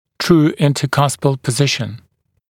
[truː ˌɪntə’kʌspl pə’zɪʃn][тру: ˌинтэ’касп(э)л пэ’зишн]истинное межбугорковое положение, правильный бугорково-фиссурный контакт